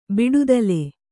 ♪ biḍudale